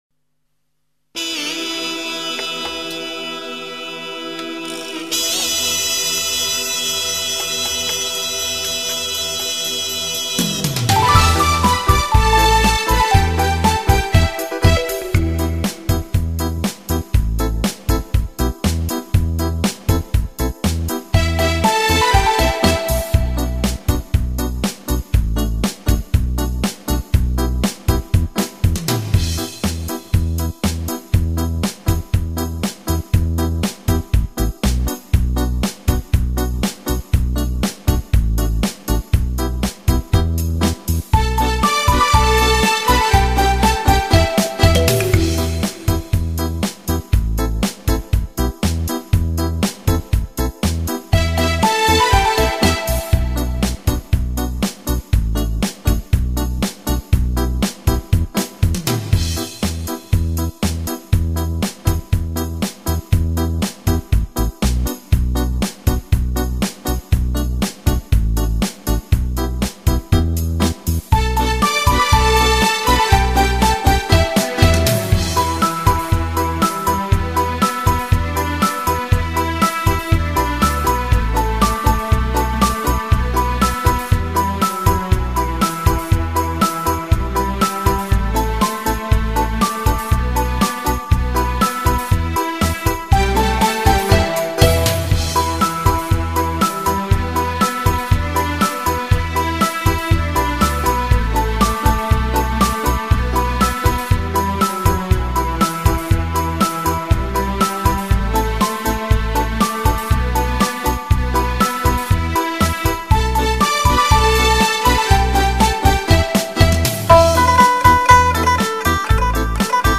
无 调式 : G 曲类